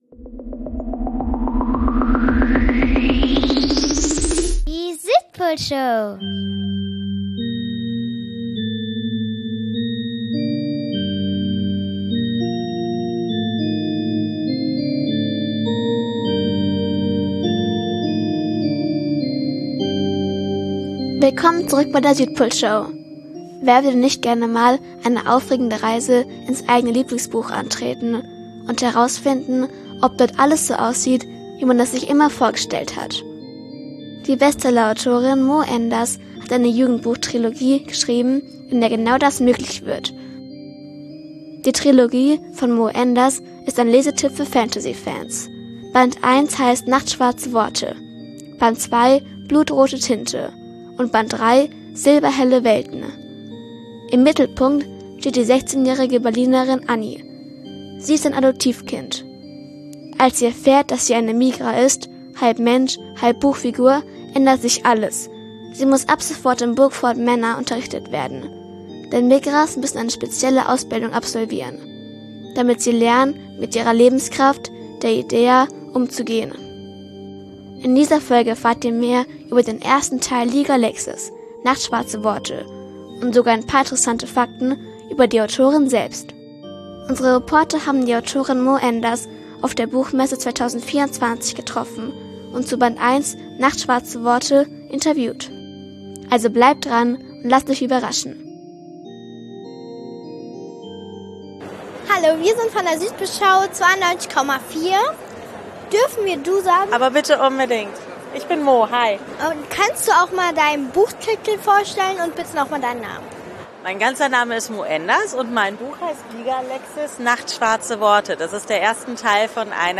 Unser Reporter und unsere Reporterin konnten sich also gar nicht darauf vorbereiten.